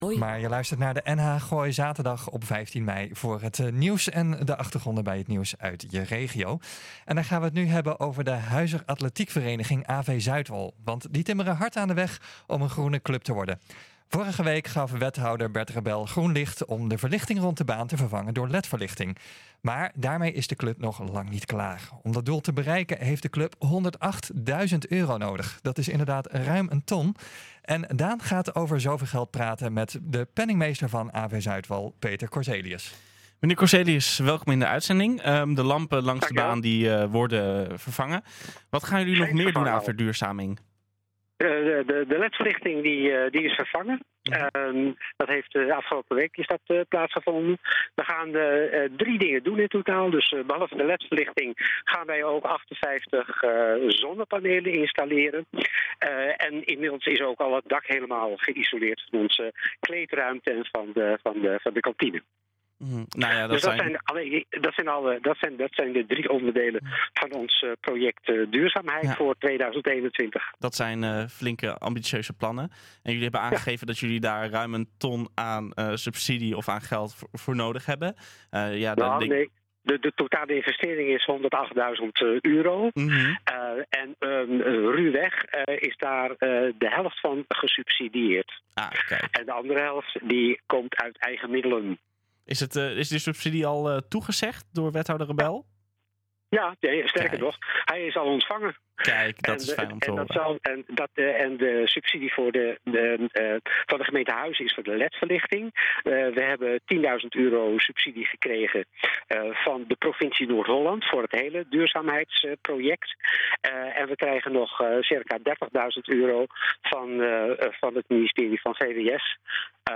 NHGooi is de streekomroep voor Gooi & Vechtstreek.